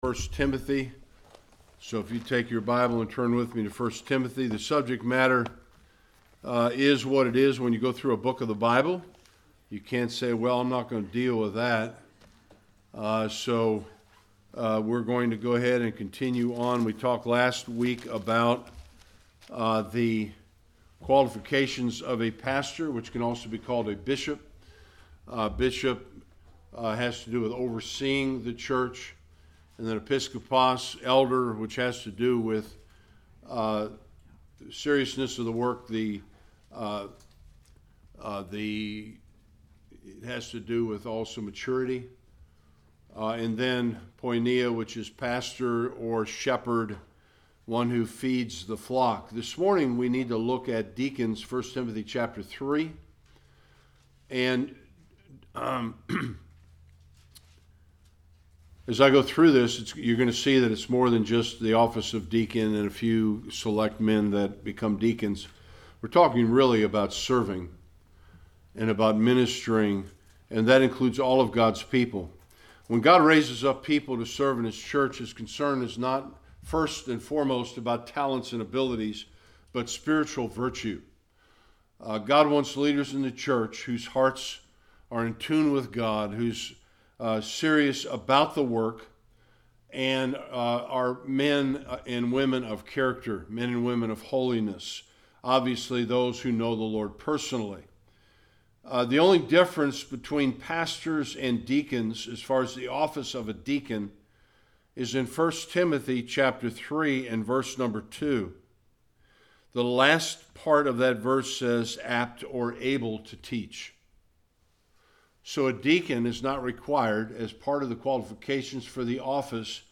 1 Timothy 3:8-18 Service Type: Sunday Worship The qualifications of a deacon who uses and not just fills the office.